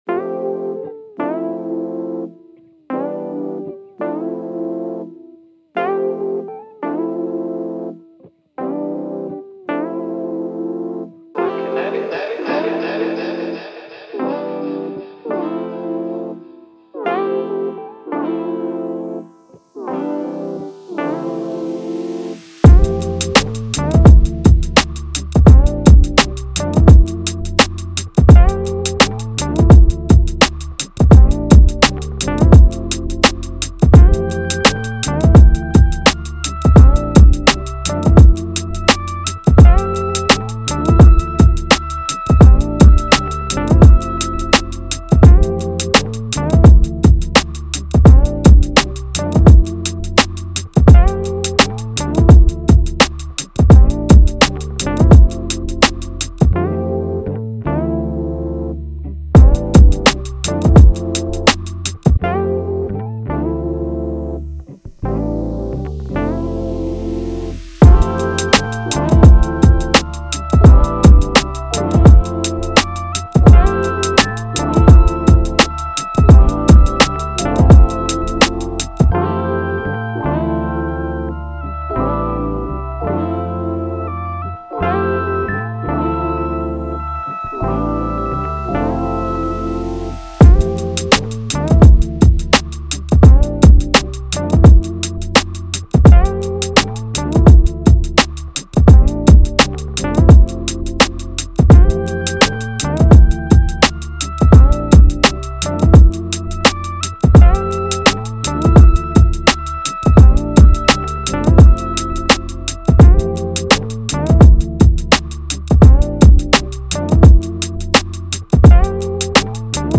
R&B
A Minor